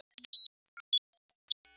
GameDevTVTutFPS/SciFiBeep HI055904.wav at main